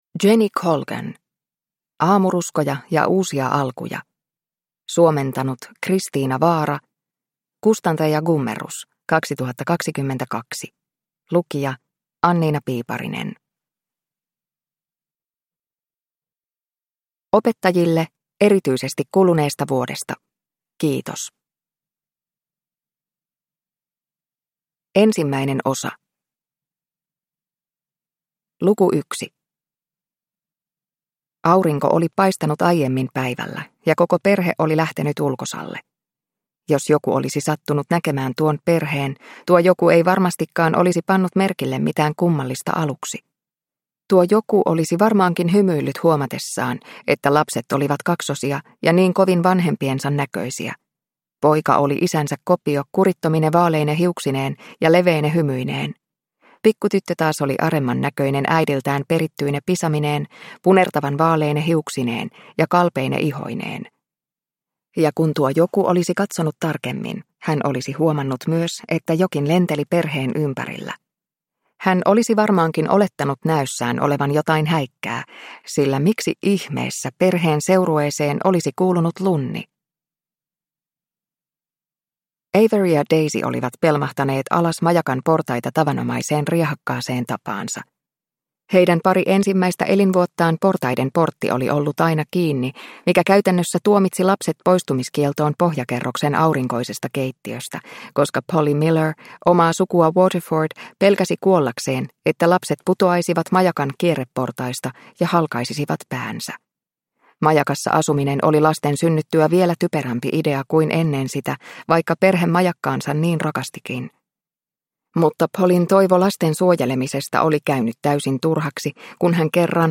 Aamuruskoja ja uusia alkuja – Ljudbok – Laddas ner